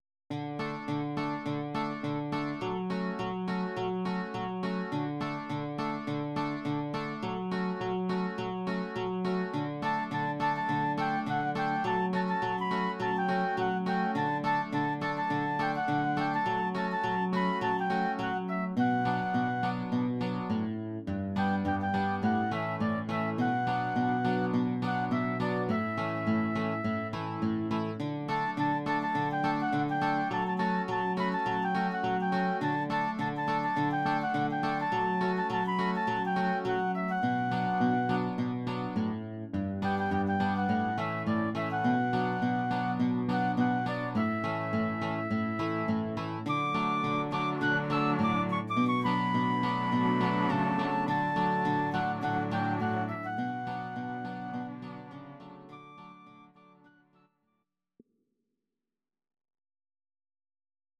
Audio Recordings based on Midi-files
Pop, 2000s